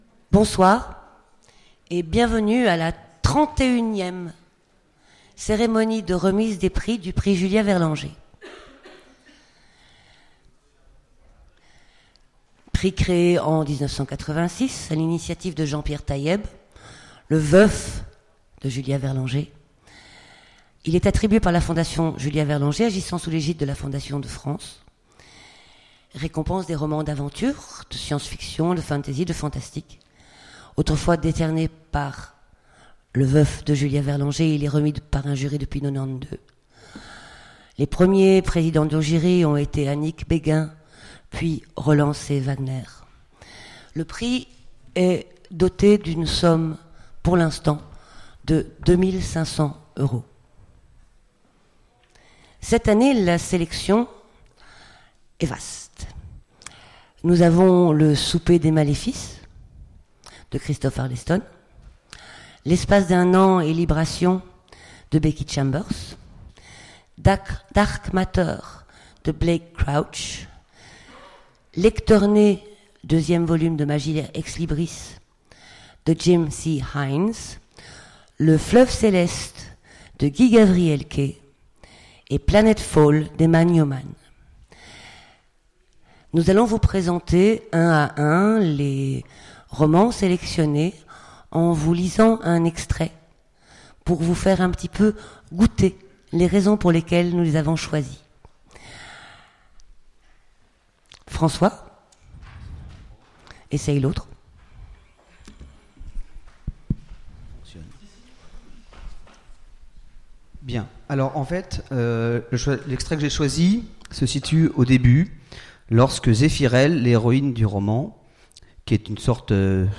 Utopiales 2017 : Remise du prix Julia Verlanger